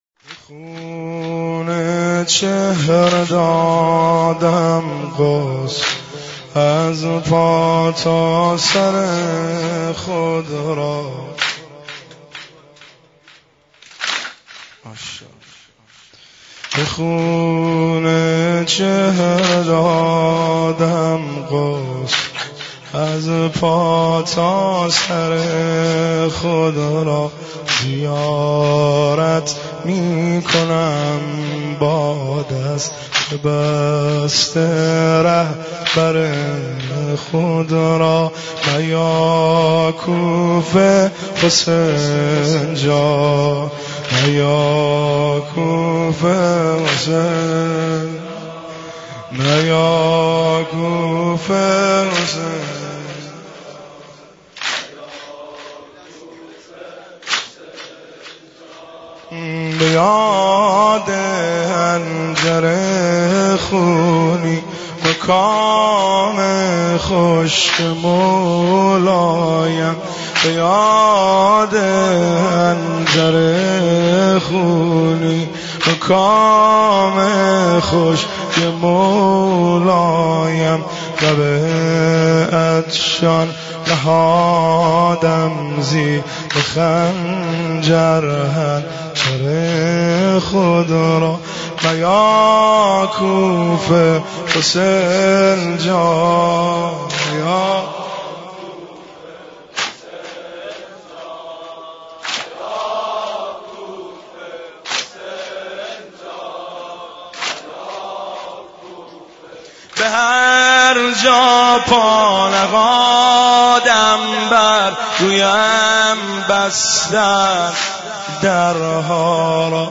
مداحی شهادت حضرت مسلم بن عقیل جواد مقدم | یک نت